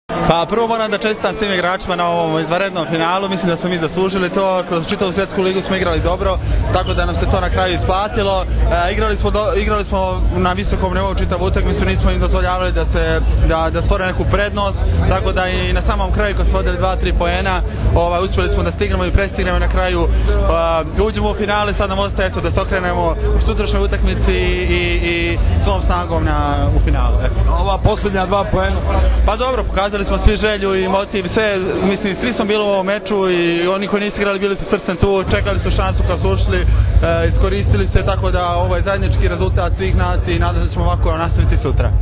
IZJAVA SAŠE STAROVIĆA